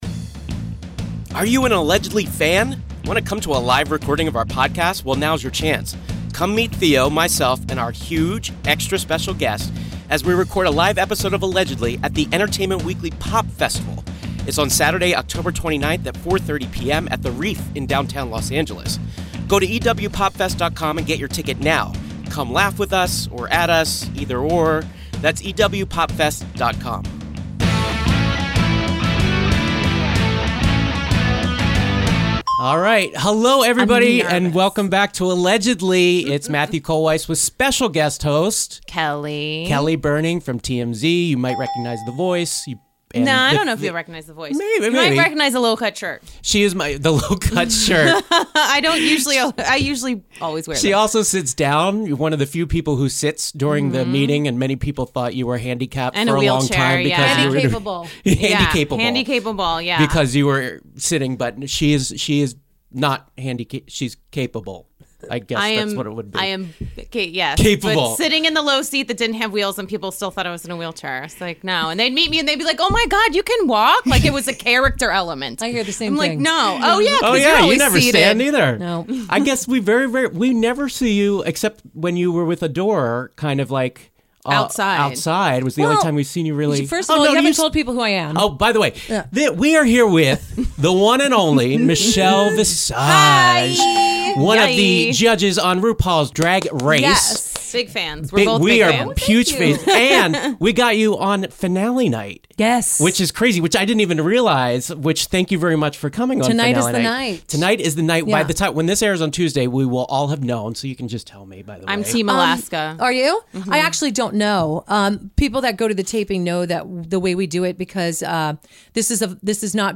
"RuPaul's Drag Race" judge and former girl band extraordinaire Michelle Visage drops in the studio this week